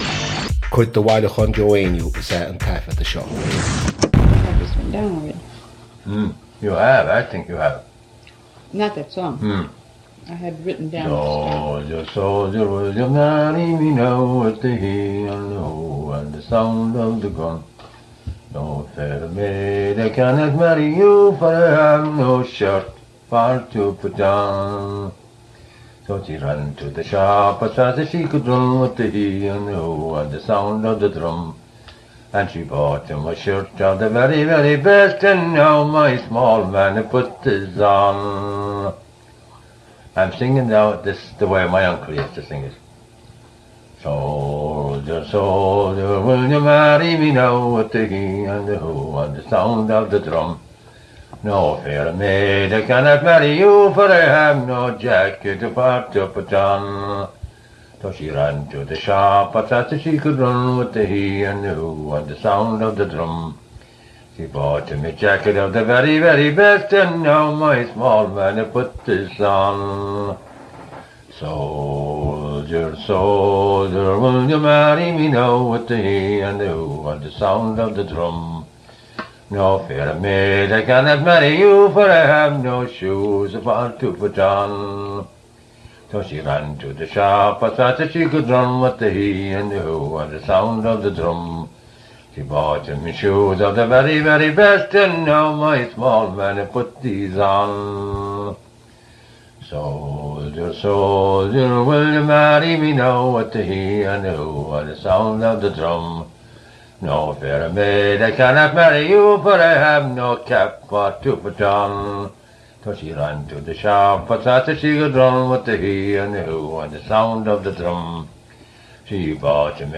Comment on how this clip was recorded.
• Suíomh an taifeadta (Recording Location): Bay Ridge, Brooklyn, New York, United States of America. • Ocáid an taifeadta (Recording Occasion): private.